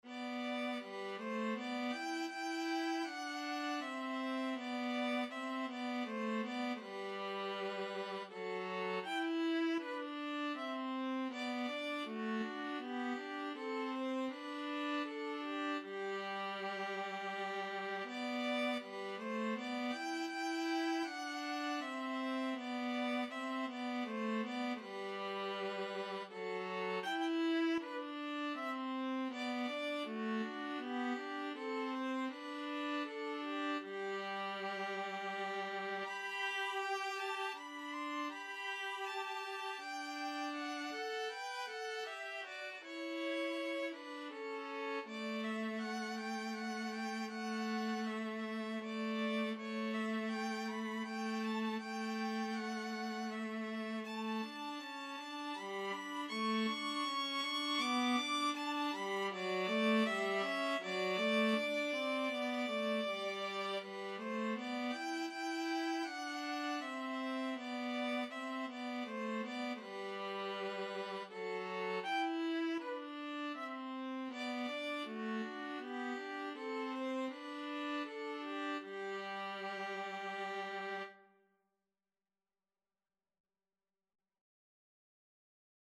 Andante
3/4 (View more 3/4 Music)
G major (Sounding Pitch) (View more G major Music for Violin-Viola Duet )
Violin-Viola Duet  (View more Easy Violin-Viola Duet Music)
Classical (View more Classical Violin-Viola Duet Music)